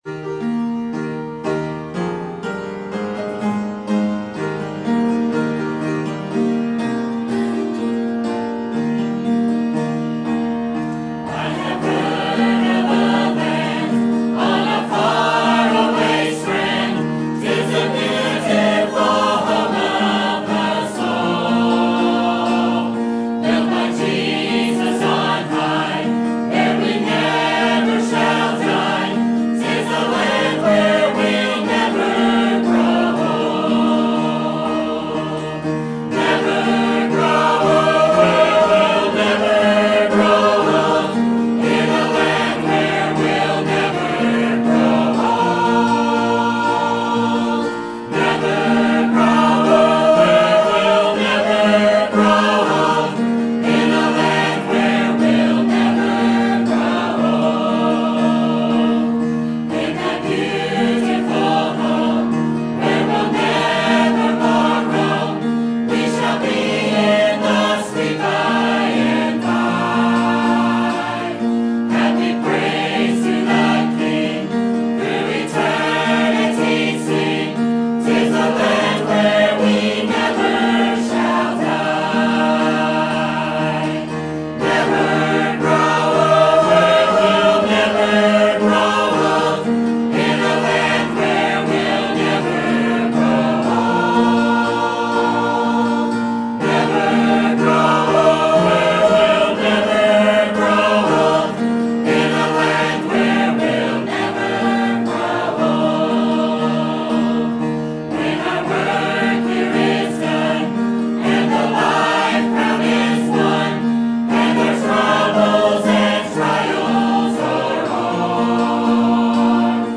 Sermon Date